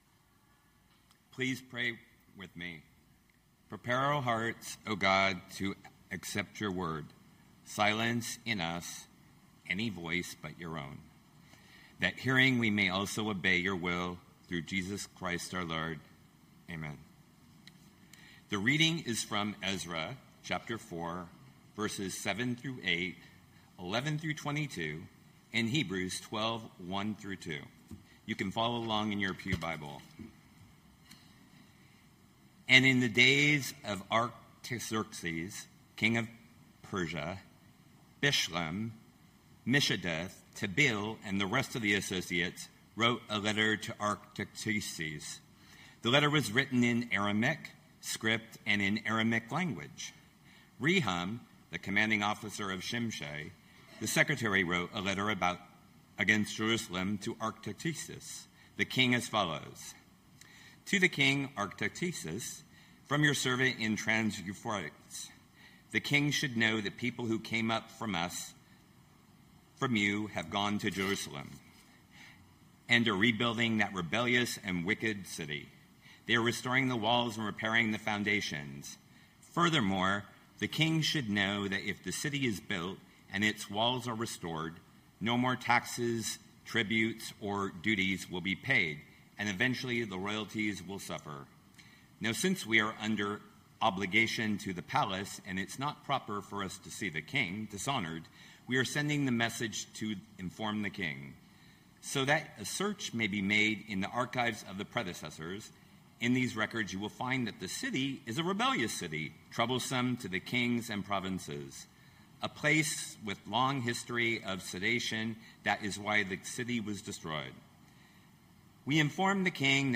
Knox Pasadena Sermons The Revelation of Resistance Sep 28 2025 | 00:23:53 1x 00:00 / 00:23:53 Subscribe Share Spotify RSS Feed Share Link Embed